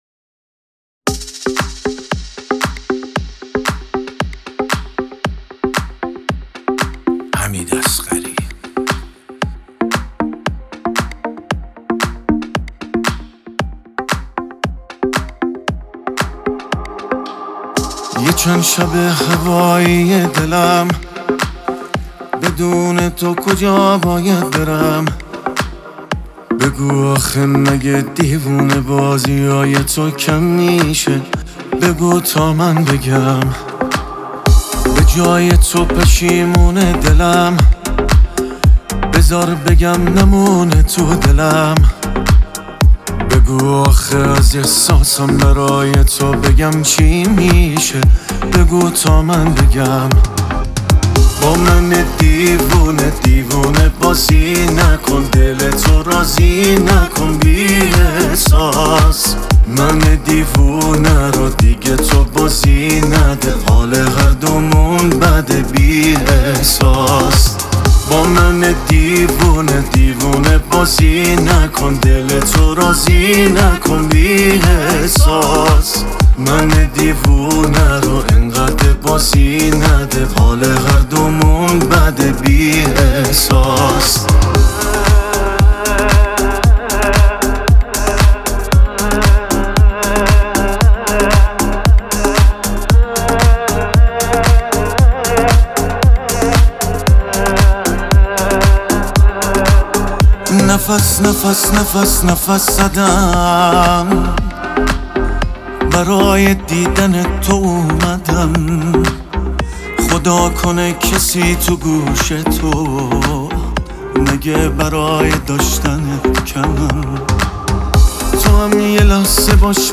غمگین